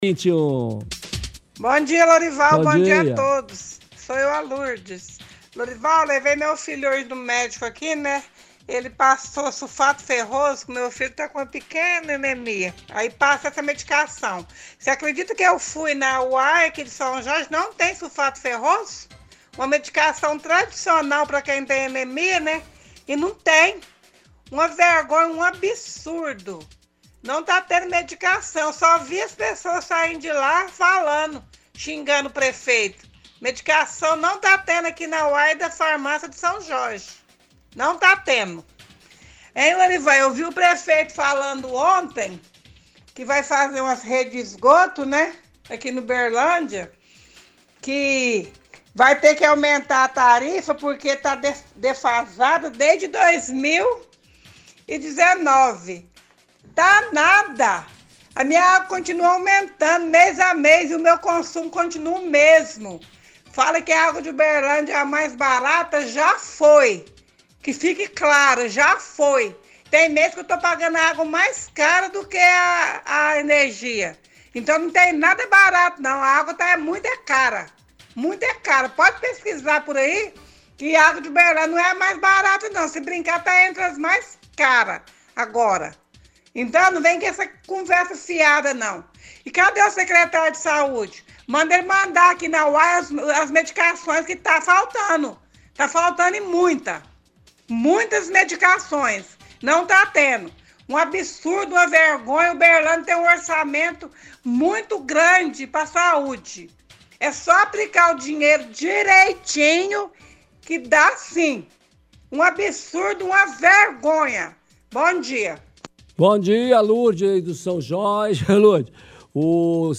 – Ouvinte reclama da UAI do São Jorge, fala que não há medicamentos disponíveis na unidade.